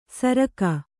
♪ saraka